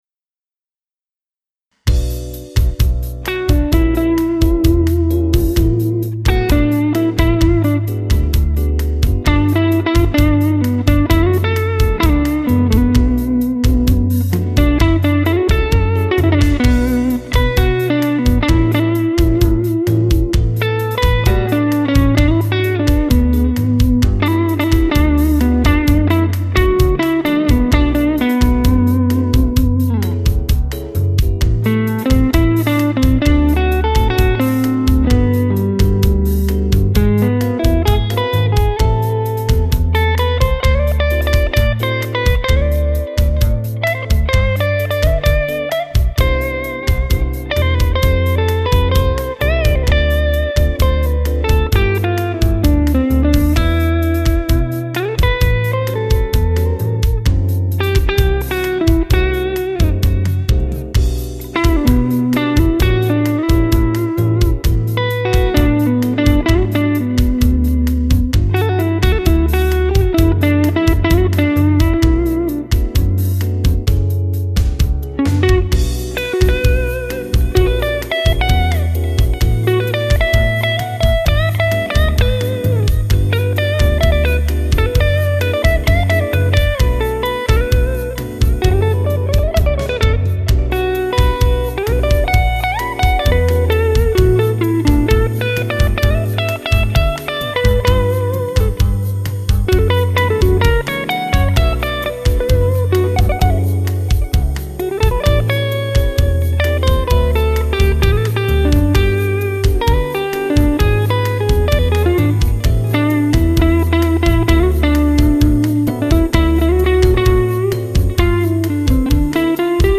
:-)) ) Habe mich da zwar durchgefuddelt, aber ein paar Gelbe sind trotzdem noch drin
Ach... ist Strat Neck-SC und Axe-Preset "Mr. Benson", leicht modifiziert, bisschen mehr Drive. Echo ist Cubase